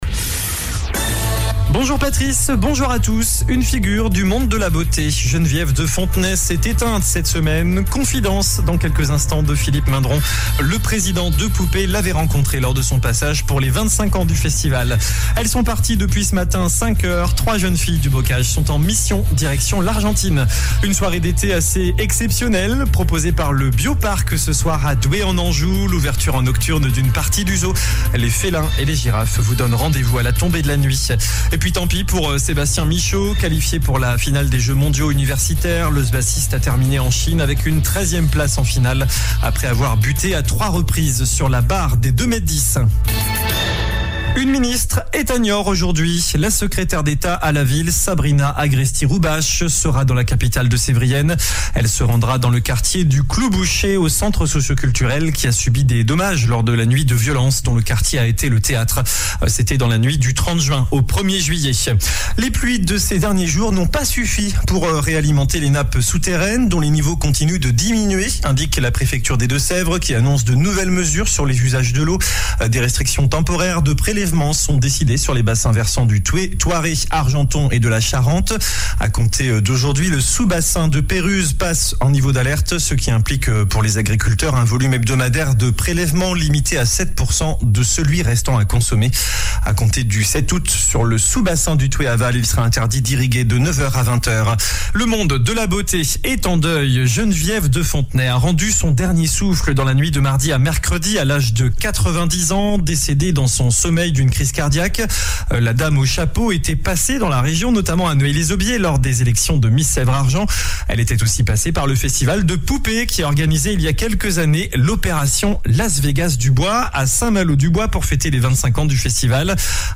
JOURNAL DU VENDREDI 04 AOÛT ( MIDI )